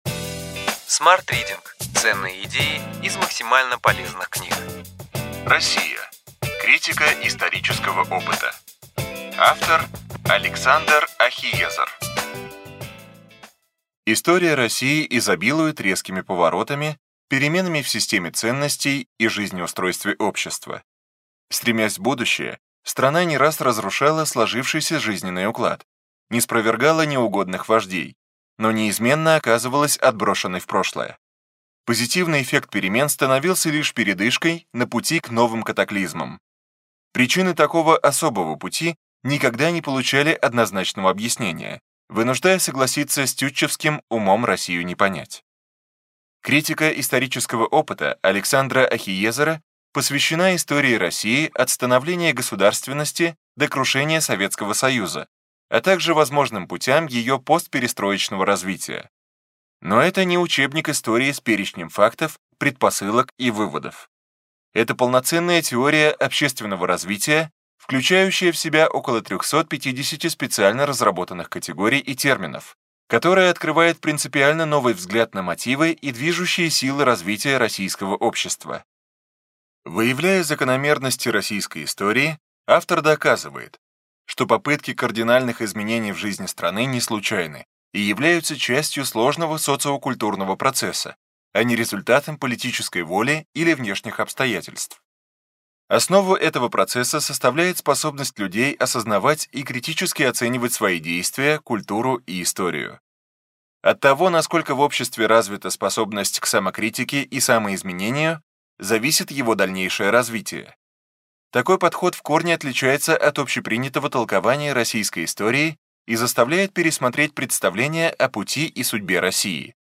Аудиокнига Ключевые идеи книги: Россия: критика исторического опыта.